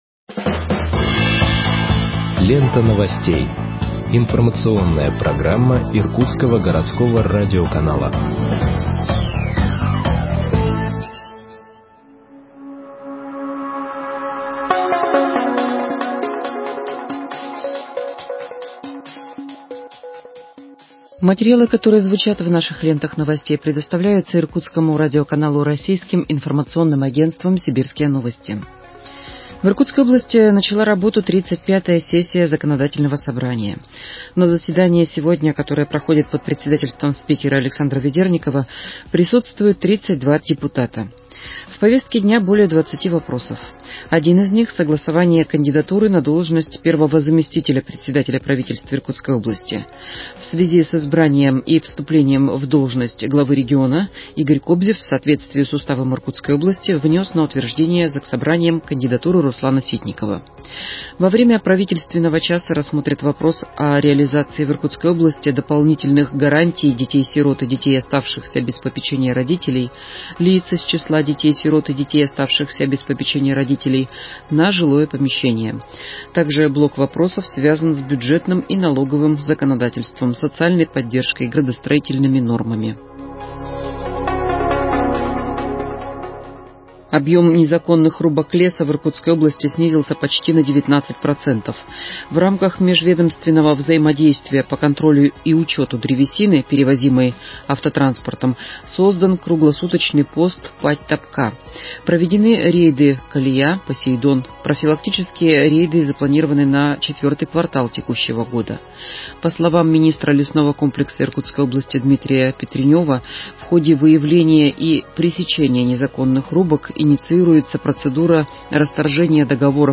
Выпуск новостей в подкастах газеты Иркутск от 21.10.2020 № 2